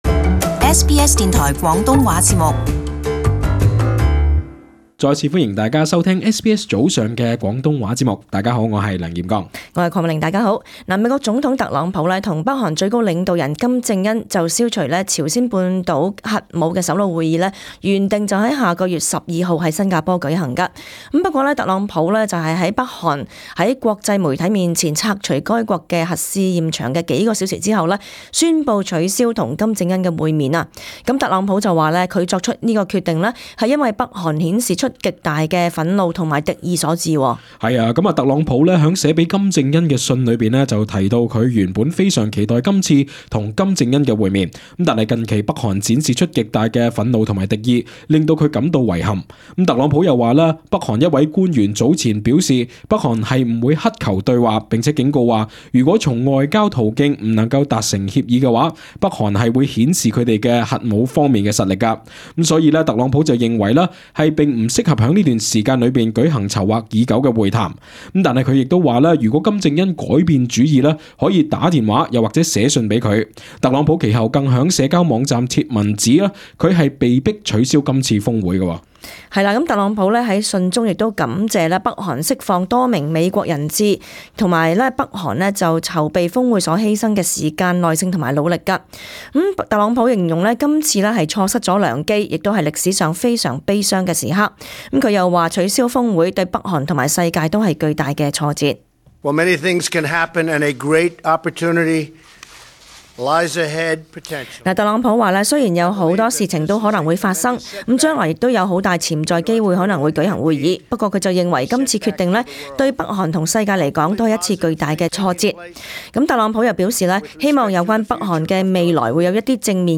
【時事報導】特朗普取消與金正恩峰會